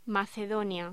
Locución: Macedonia